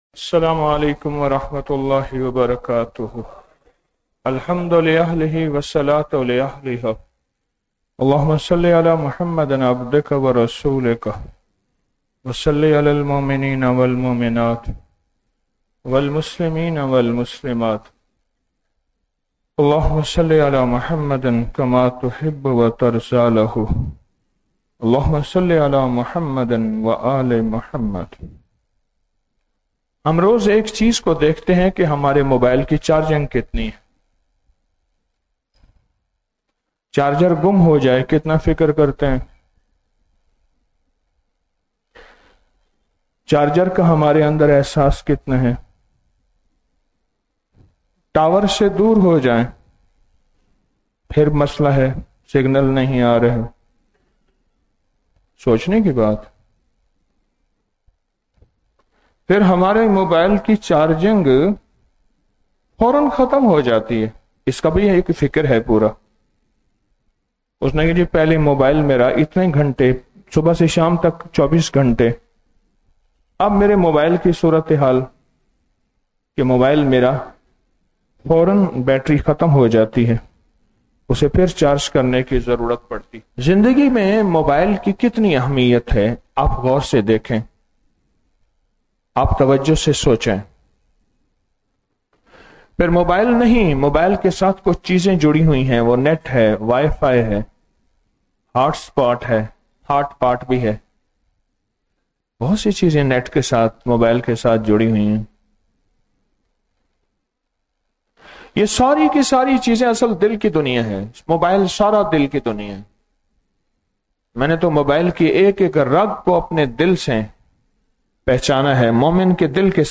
01 سوا کروڑ سورۃ الاخلاص |11 ستمبر 2025 | شبِ جمعہ محفل